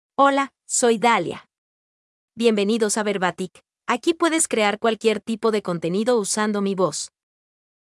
FemaleSpanish (Mexico)
Dalia — Female Spanish AI voice
Dalia is a female AI voice for Spanish (Mexico).
Voice sample
Listen to Dalia's female Spanish voice.
Dalia delivers clear pronunciation with authentic Mexico Spanish intonation, making your content sound professionally produced.